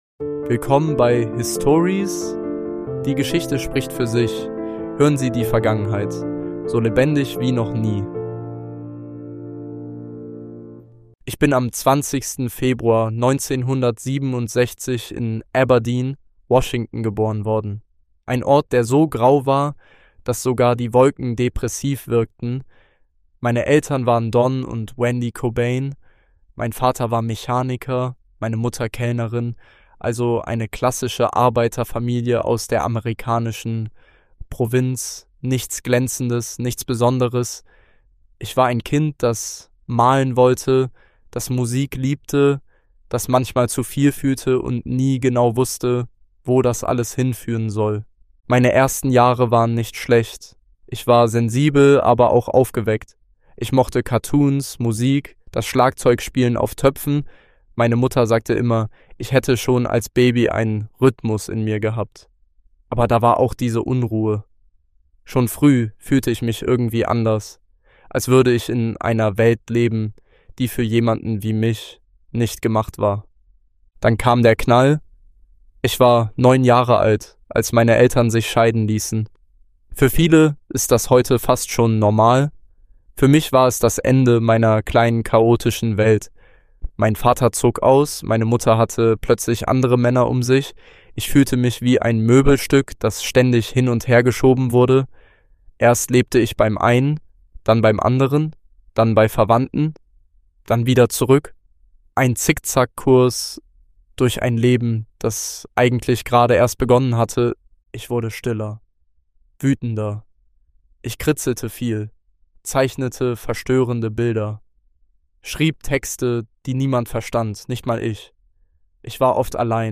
In dieser Episode erzählt Kurt Cobain selbst von seiner Kindheit, dem Aufstieg mit Nirvana, den Kämpfen mit Ruhm und inneren Dämonen – bis zu seinem tragischen Ende. Ein intimer Blick hinter die Fassade des Grunge-Idols.